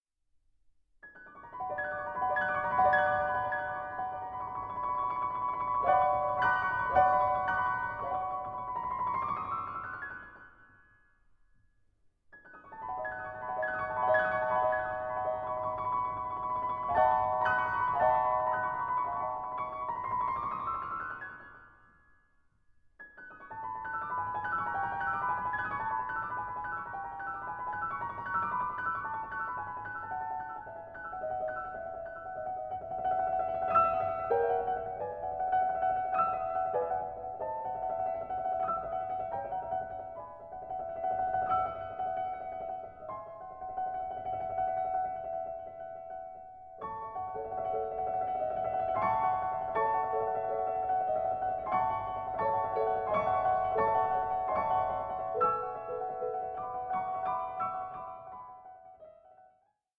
INTROSPECTIVE PIECES ABOUT LOVE IN ALL ITS MANIFESTATIONS